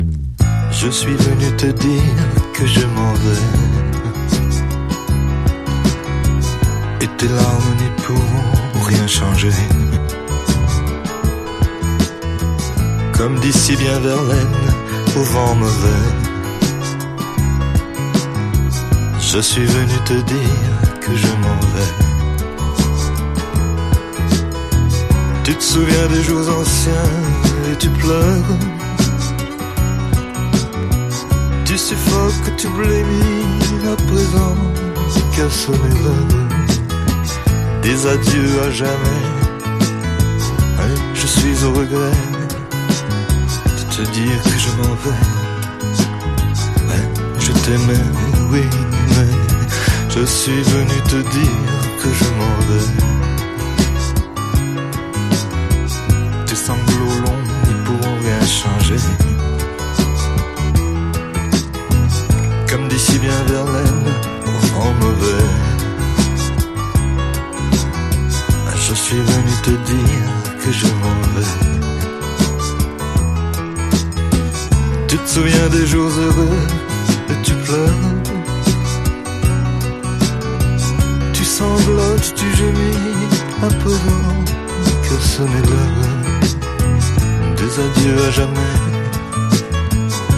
迸る哀愁とダンディズム極まる